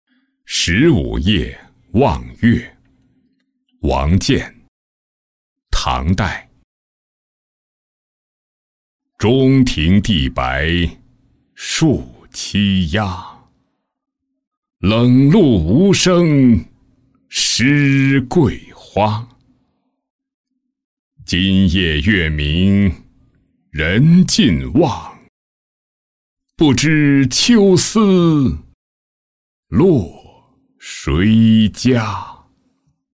十五夜望月-音频朗读